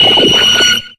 Audio / SE / Cries / ELGYEM.ogg